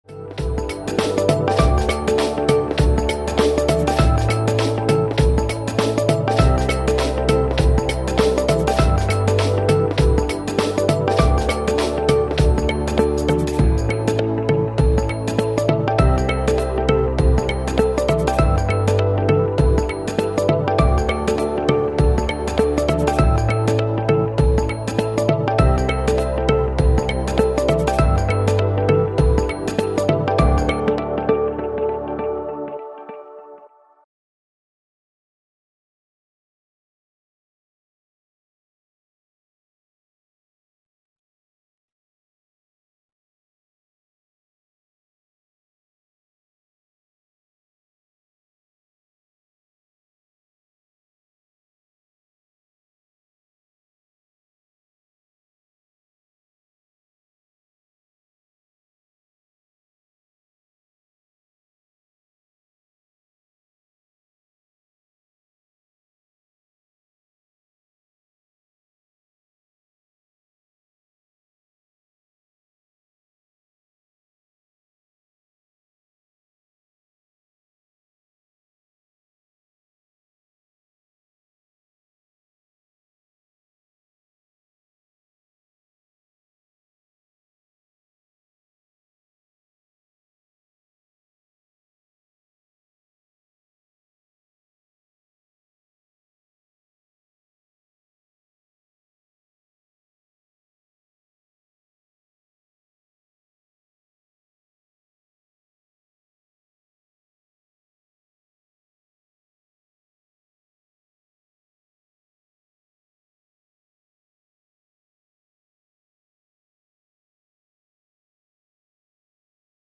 Audio de las conferencias